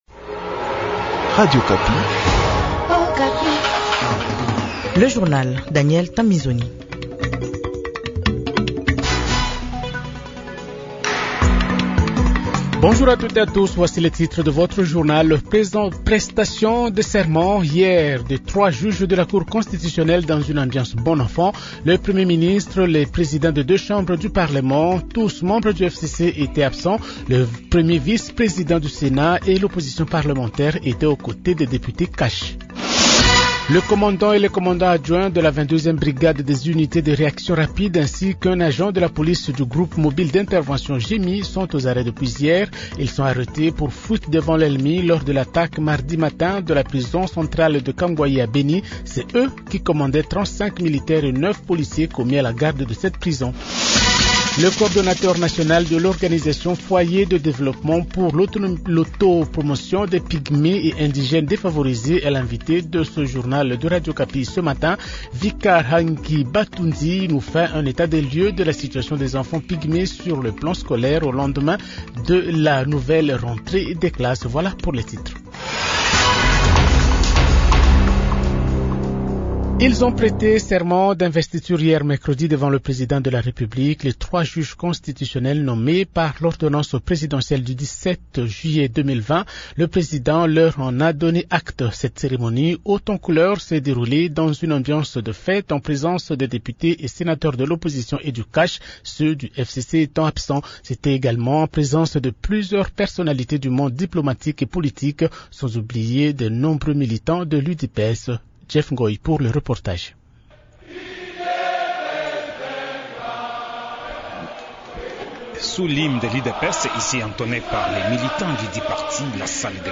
Journal Francais Matin